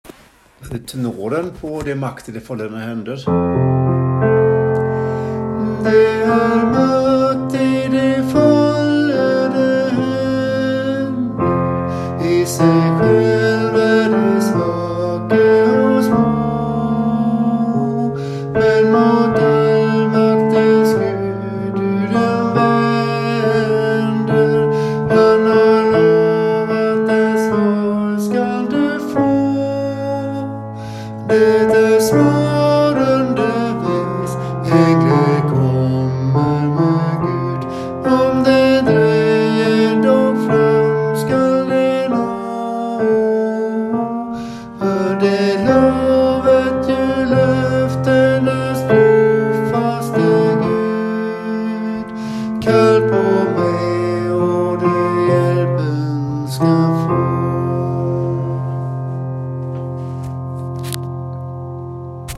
Det är makt i de follede hender ten
det er makt_tenor.mp3